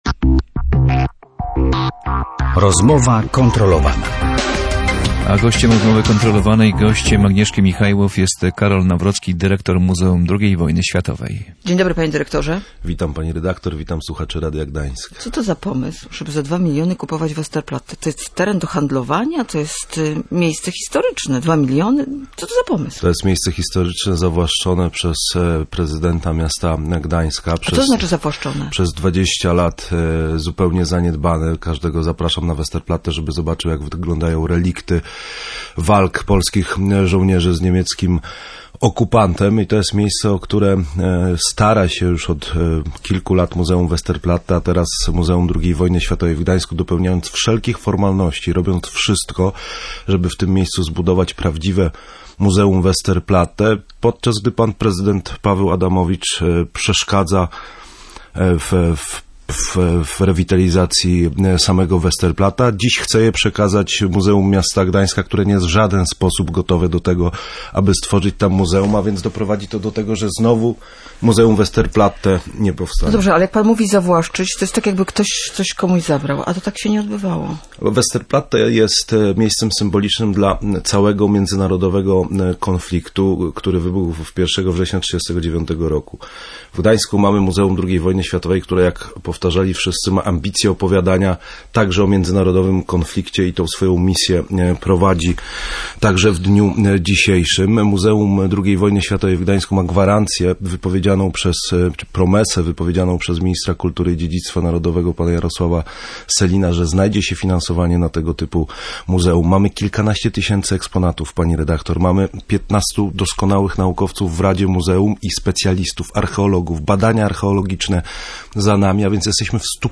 Dyrektor Muzeum II Wojny Światowej był Karol Nawrocki był gościem Rozmowy Kontrolowanej.